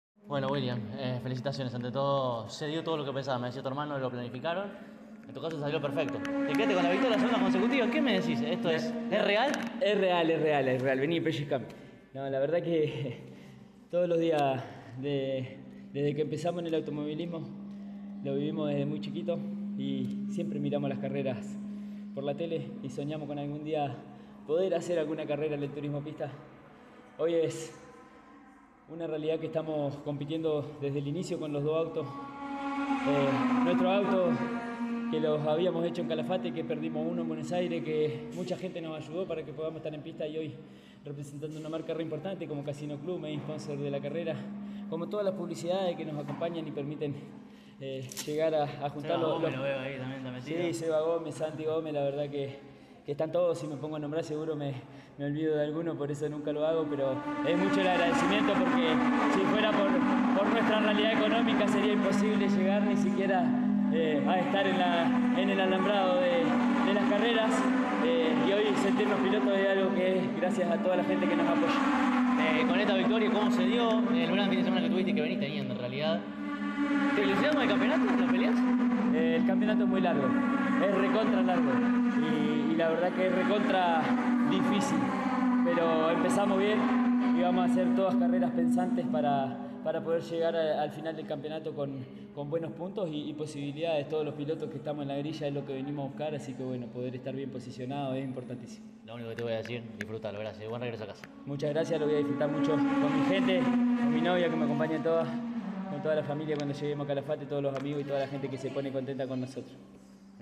El TP disputó la segunda fecha de su calendario 2026 en el autódromo de Toay (provincia de La Pampa), y allí estuvo CÓRDOBA COMPETICIÓN.